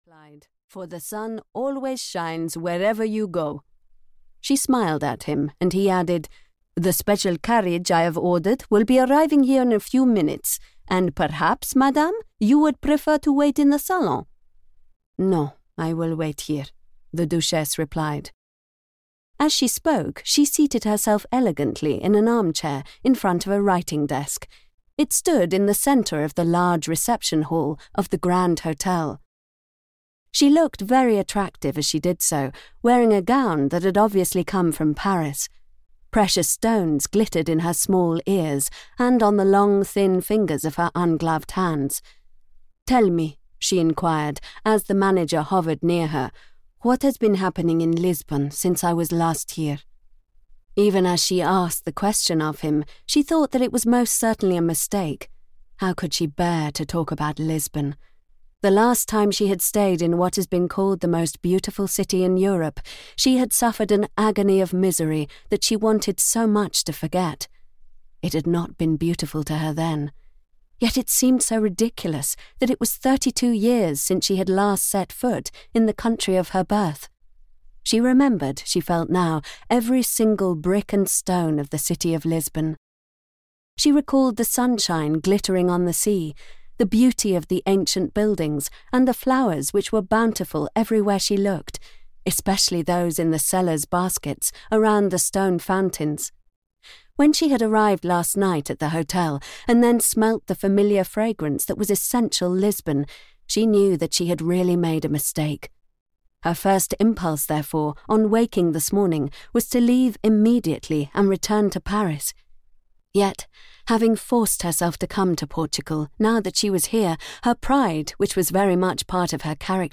Lovers in Lisbon (EN) audiokniha
Ukázka z knihy